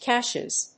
/ˈkʰæʃɨz(米国英語), kæˈʃeɪz(英国英語)/